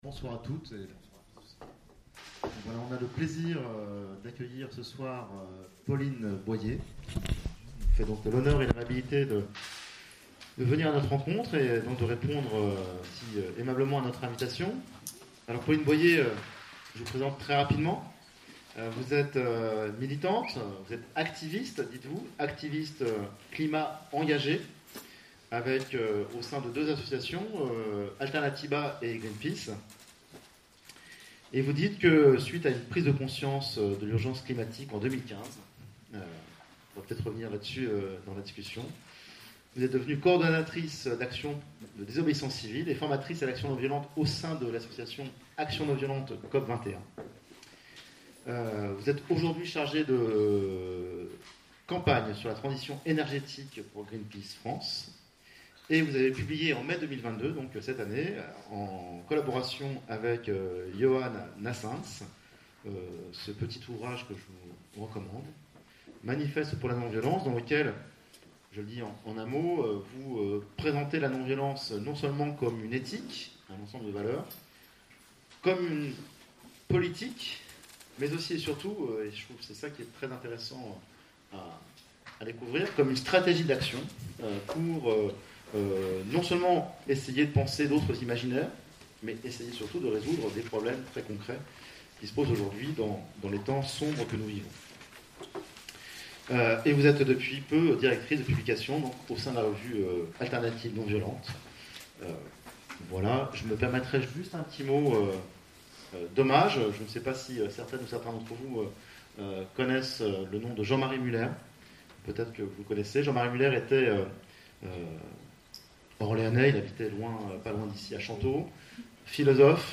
Conférences et cafés-philo, Orléans
CONFÉRENCES PHILOMANIA La lutte non violente pour la justice climatique Play Episode Pause Episode Mute/Unmute Episode Rewind 10 Seconds 1x Fast Forward 30 seconds 00:00 / 1:30:46 Subscribe Share RSS Feed Share Link Embed